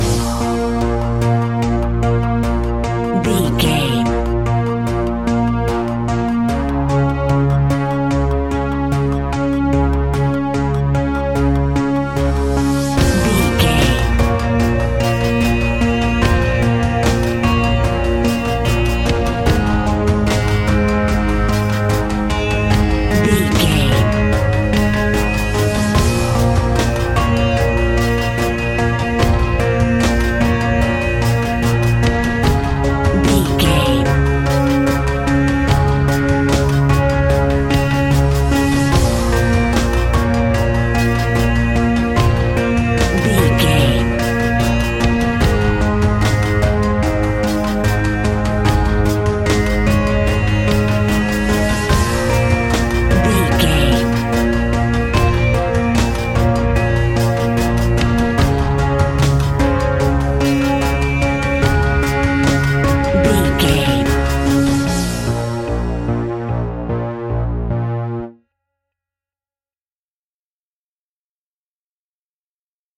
Aeolian/Minor
B♭
ominous
dark
haunting
eerie
synthesiser
drums
ticking
electronic music
electronic instrumentals